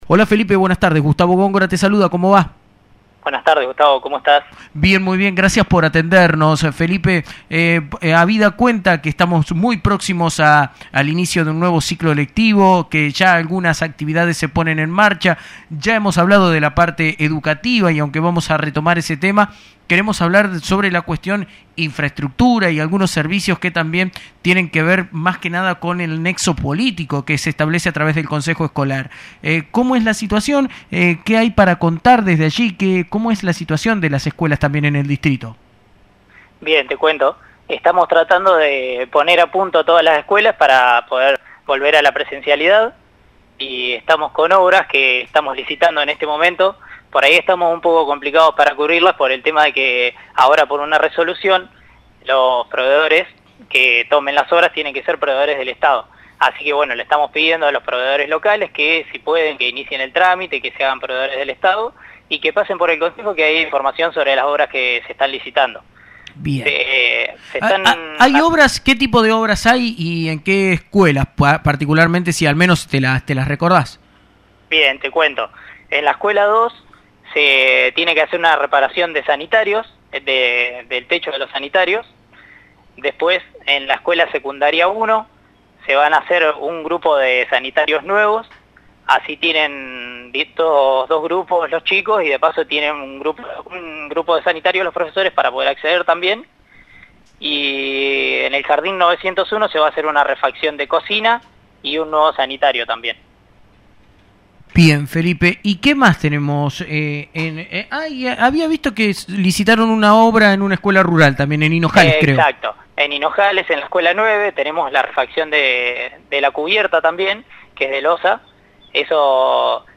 En dialogo con la Emisora Municipal, el Tesorero del Consejo Escolar de Pila, Felipe Burs, se refirió al retorno de un nuevo inicio del ciclo lectivo en lo que respecta a la situación actual que se encuentran las escuelas del distrito.